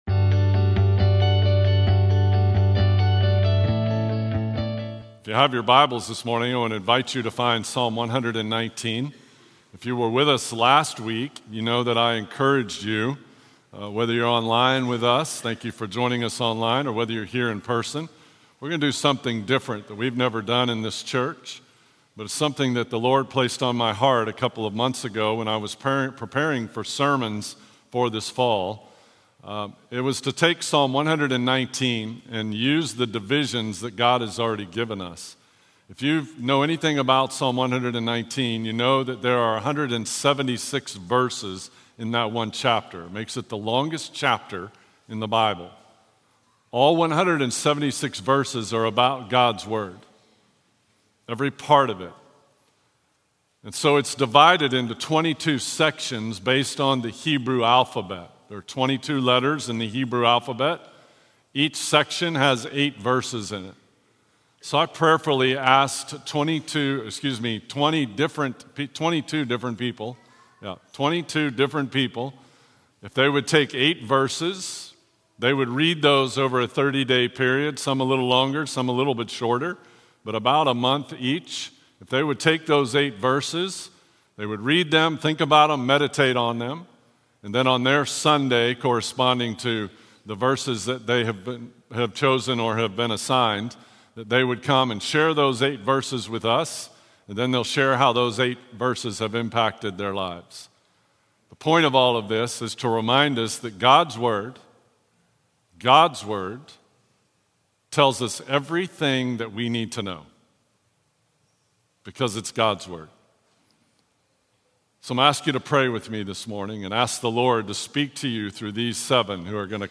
Simonton Sermon Podcast